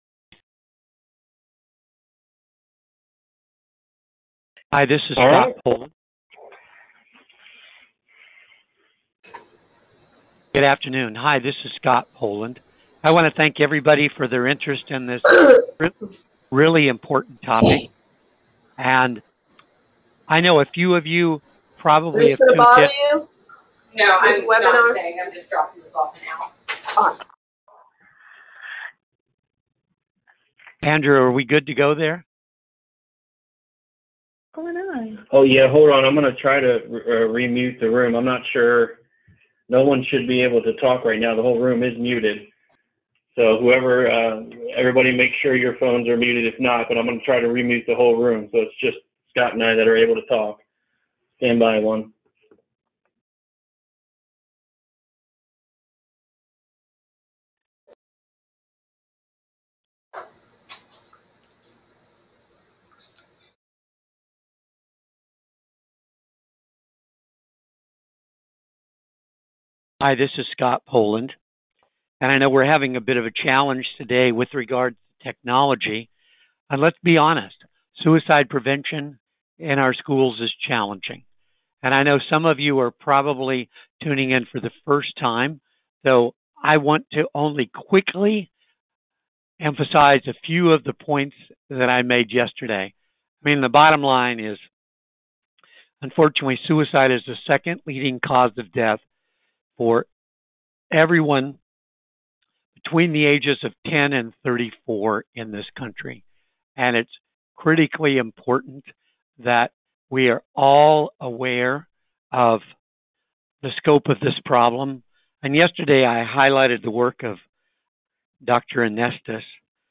Webinar: Suicide Prevention in Schools